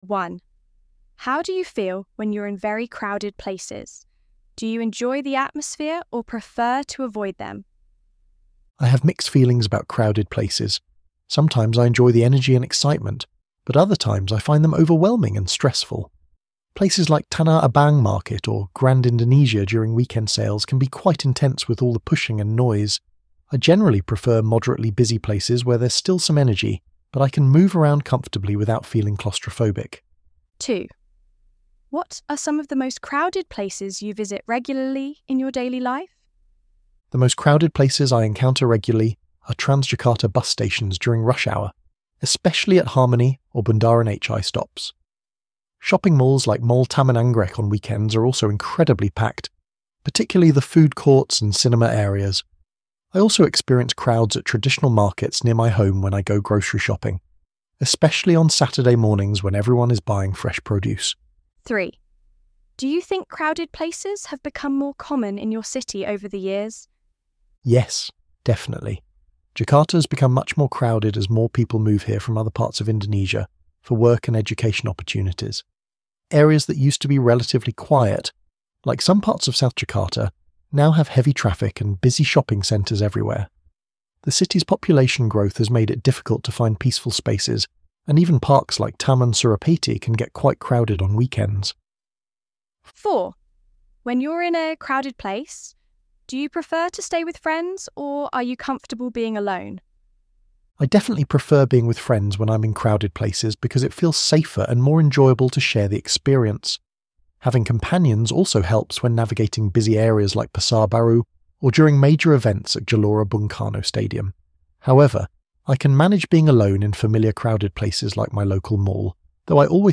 ElevenLabs_14_September.mp3